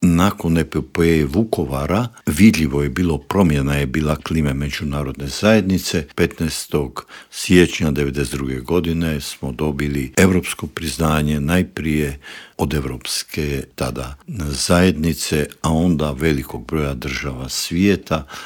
ZAGREB - Uoči 34. godišnjice međunarodnog priznanja Hrvatske i 28. obljetnice završetka mirne reintegracije hrvatskog Podunavlja u Intervju Media servisa ugostili smo bivšeg ministra vanjskih poslova Matu Granića, koji nam je opisao kako su izgledali pregovori i što je sve prethodilo tom 15. siječnju 1992. godine.